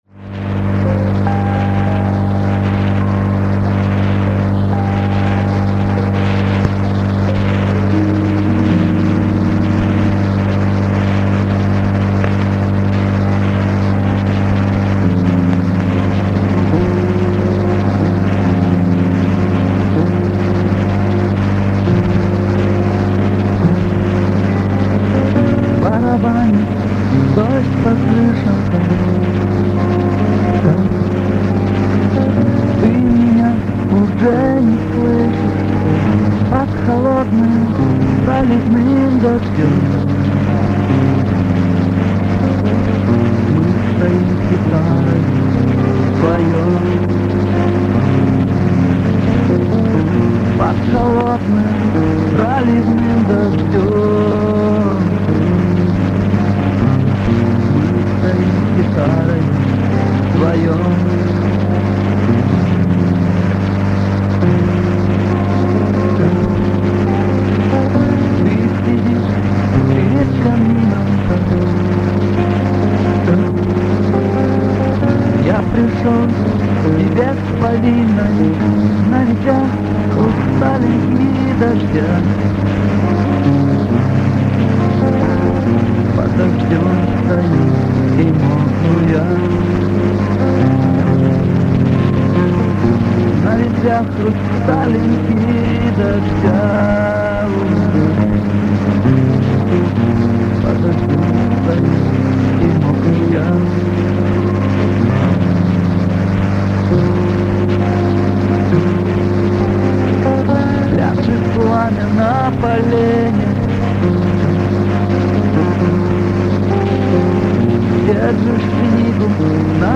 -У меня есть записи с радио-хулиганского эфира, 90-x годов.
Записи очень плохого качества..
Оцифрованные версии (моно) именно ЭТОЙ песни, именно ЭТОГО исполнителя - ниже..